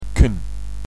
canreduit.mp3